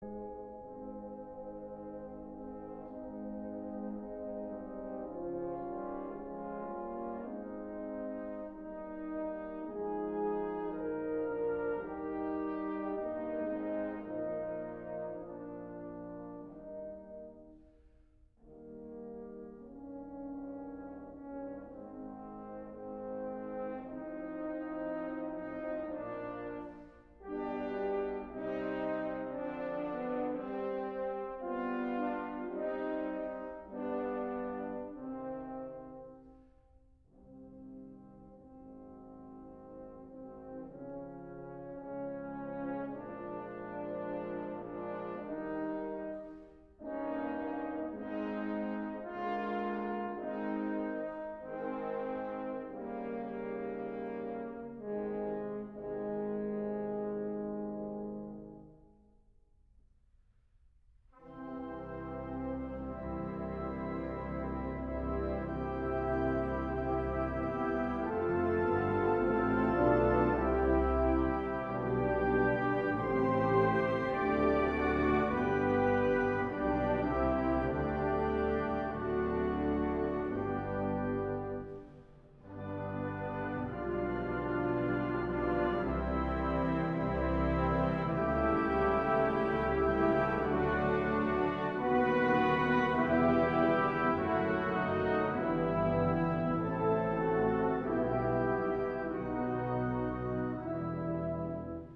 dykes-eternal-father-strong-to-save-the-navy-hymn-the-presidents-own-us-marine-band-audiotrimmer.com_.mp3